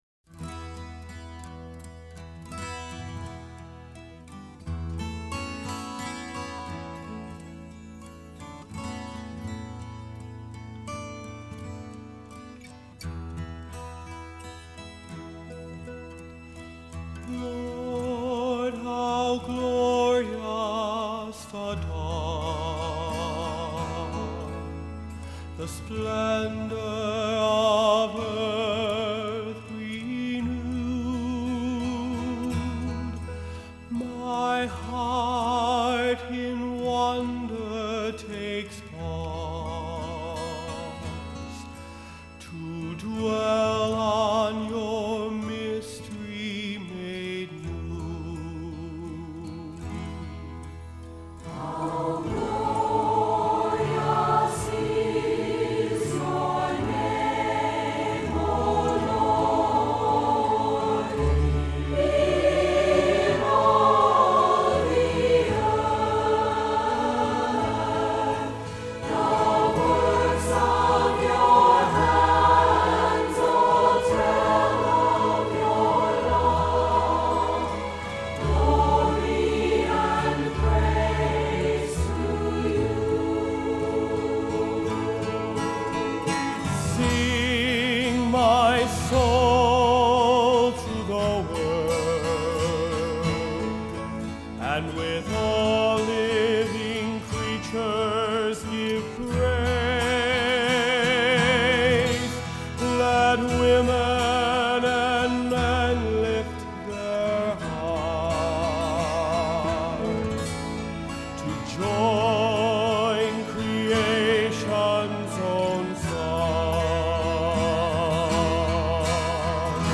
I was struck by the sense of awe in it.